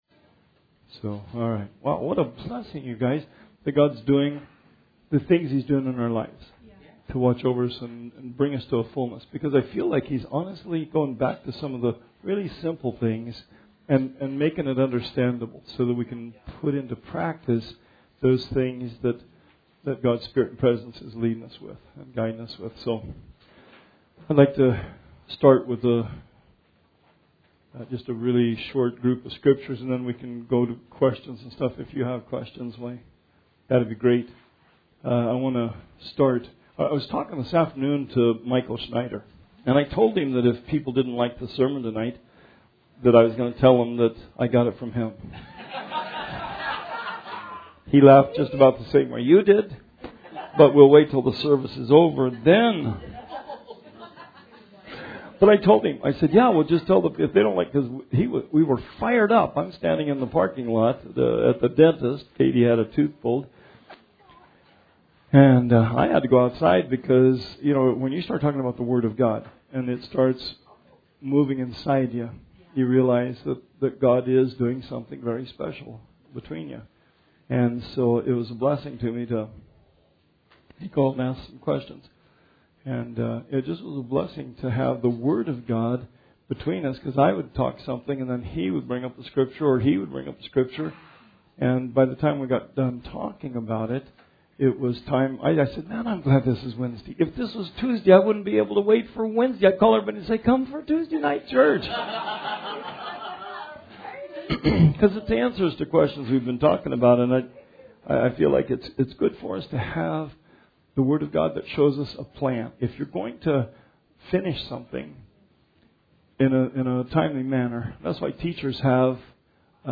Bible Study 6/12/19